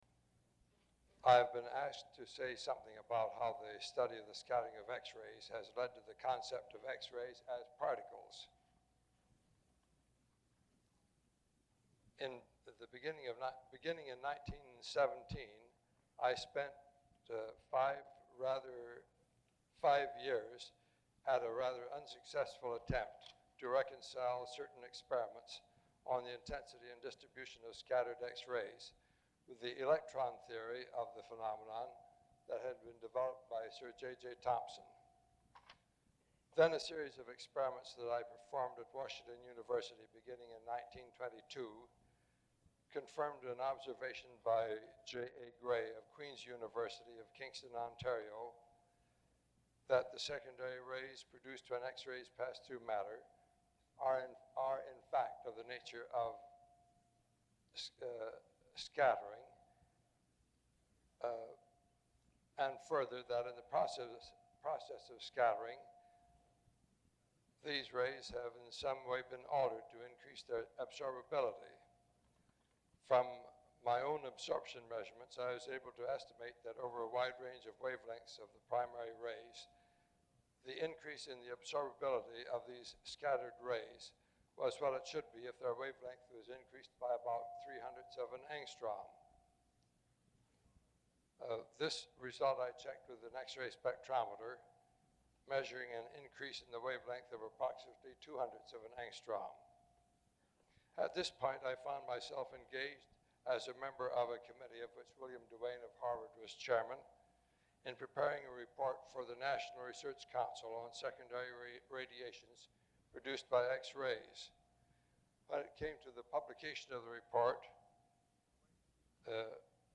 Lecture Title